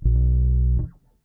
bass9.wav